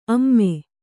♪ amme